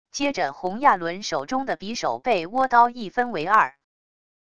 接着洪亚伦手中的匕首被倭刀一分为二wav音频生成系统WAV Audio Player